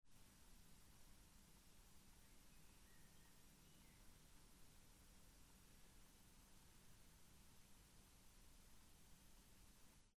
Uskutočnené v programe SoundForge, použitý mikrofón je Shure C607, merané to bolo v zatvorenej izbe (okná, dvere) asi tak o 11tej hodine ráno.
Hlučnosť mojej izby -57dB (merané v rohu izby, počítač je zapnutý)
Hlučnosť počítača -55dB (merané 50cm od monitora)
Hlučnosť počítača pri práci -29dB (merané 50cm od monitora, zvuky čo počuť, sú pre porovnanie - je to klikanie na myši, a písanie na klávesnici)